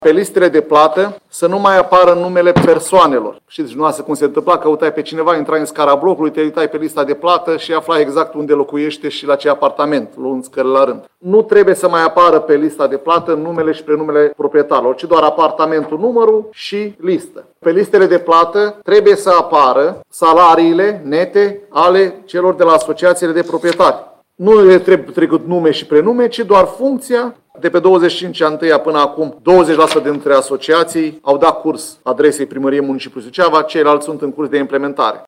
Viceprimarul LUCIAN HARȘOVSCHI a precizat că regulamentul de organizare și funcționare al asociațiilor de proprietari a fost modificat, în acest sens, prin votul Consiliului Local.